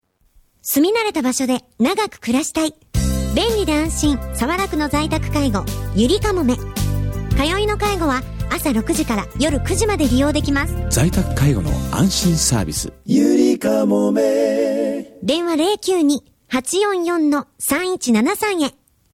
ラジオCM